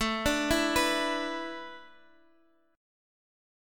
Asus2sus4 Chord (page 3)
Listen to Asus2sus4 strummed